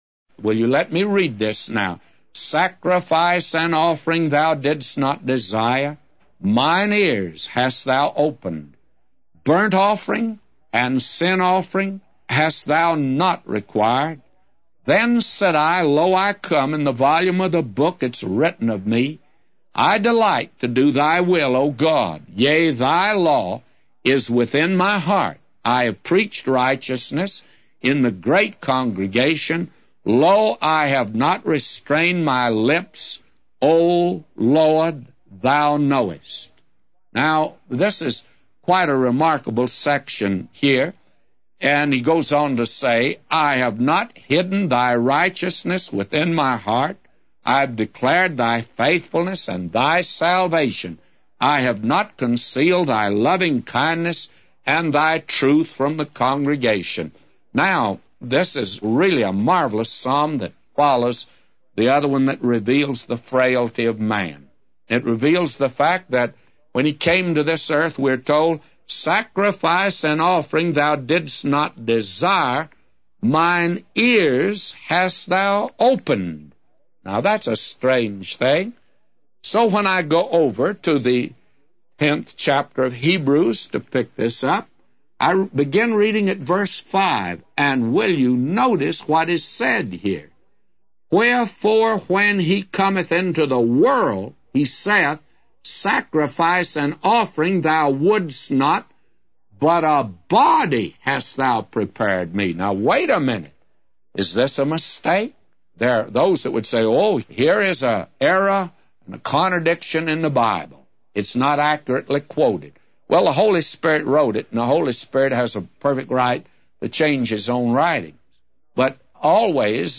A Commentary By J Vernon MCgee For Psalms 40:1-999